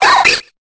Cri de Riolu dans Pokémon Épée et Bouclier.